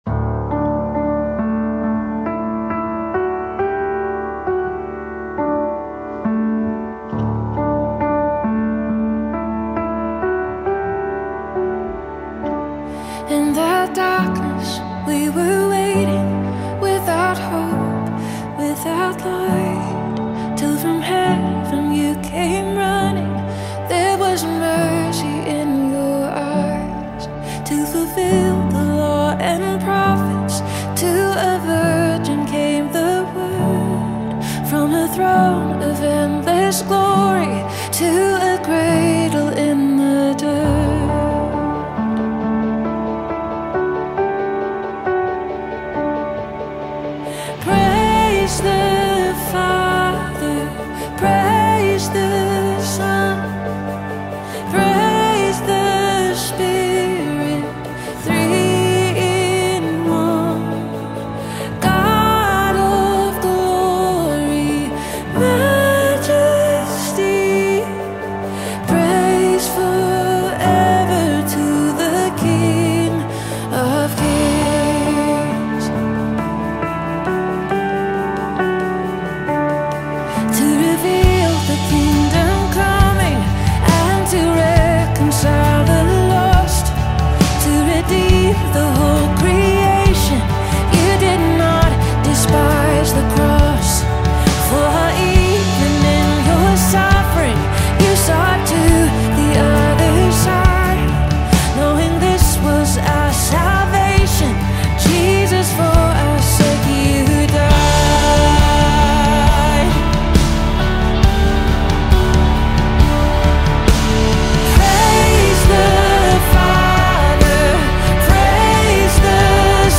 is a stirring anthem featuring lead vocals